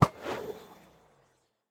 endereye_launch1.ogg